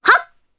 Requina-Vox_Jump_jp.wav